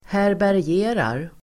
Uttal: [härbärj'e:rar]